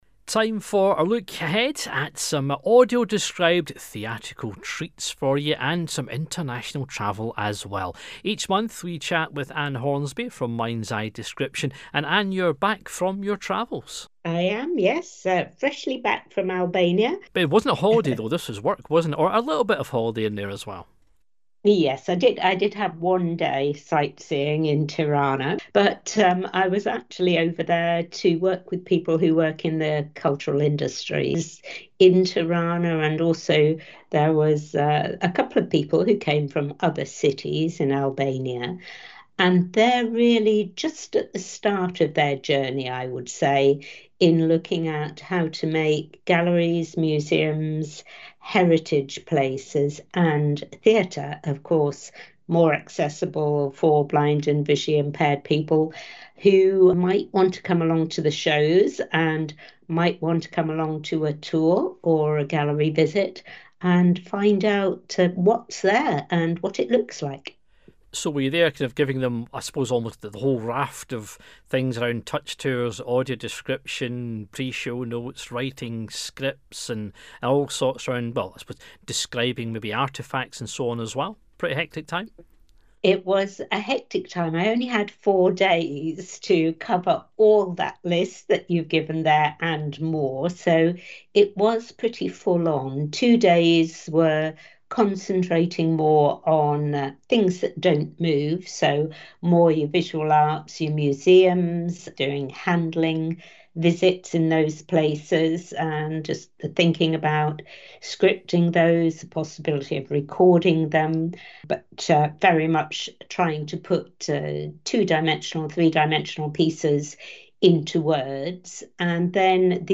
RNIB Connect / Some Audio Described Highlights From MindsEye Description.